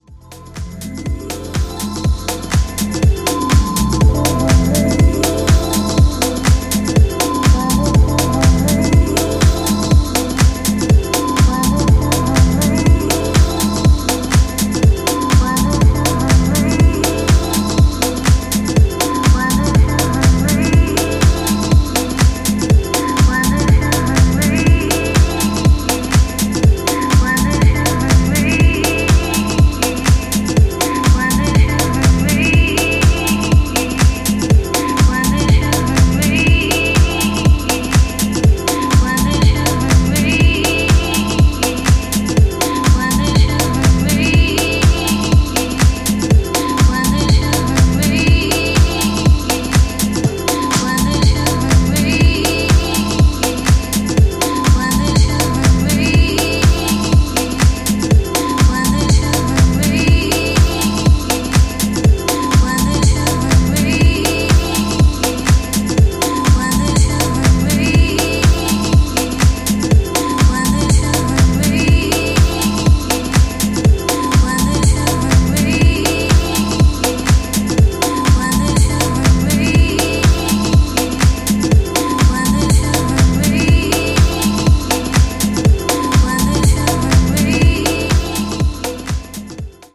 全4トラック共にグレイトな仕上がりで、良質でフロア重視のディープ・ハウスを披露。
ジャンル(スタイル) DEEP HOUSE